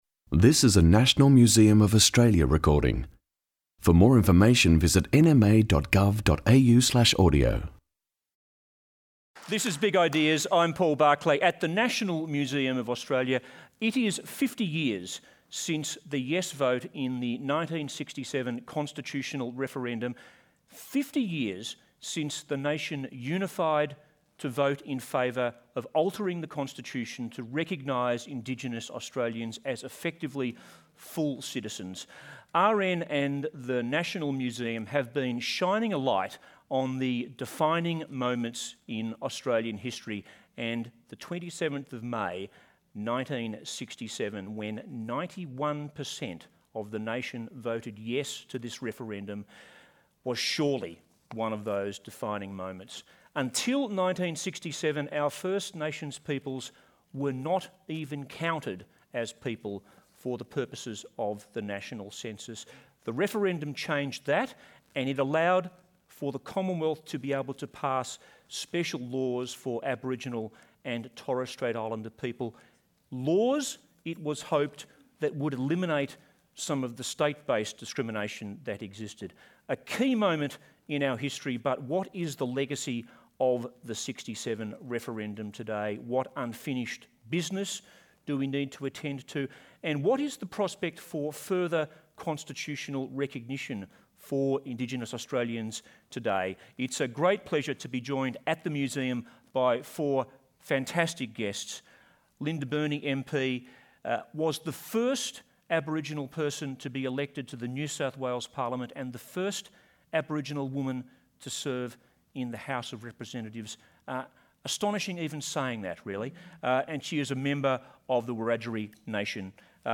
Defining Moments in Australian History 24 May 2017 Defining Moments 1967 referendum panel discussion Fifty years after the landmark 1967 referendum, has change truly come for Aboriginal and Torres Strait Islander peoples, or is it coming still?